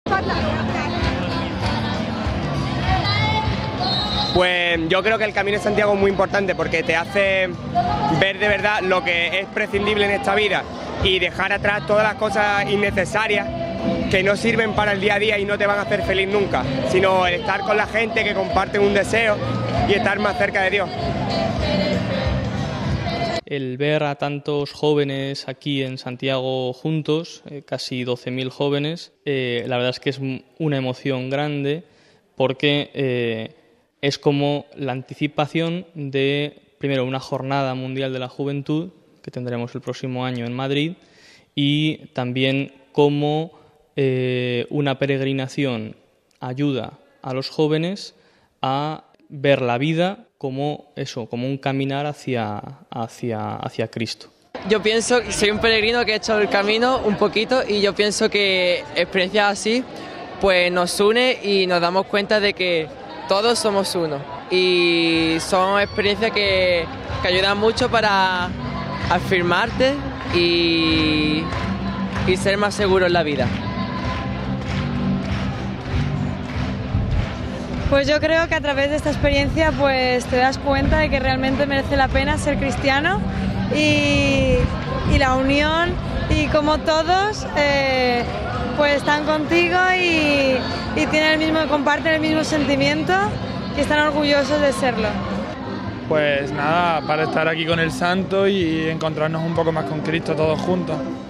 Oigamos a continuación, el ambiente que se vivió ayer por la tarde en el escenario de la plaza del Obradoiro y los testimonios de algunos muchachos y muchachas que estuvieron presentes en este primer acto de acogida en Santiago de Compostela RealAudio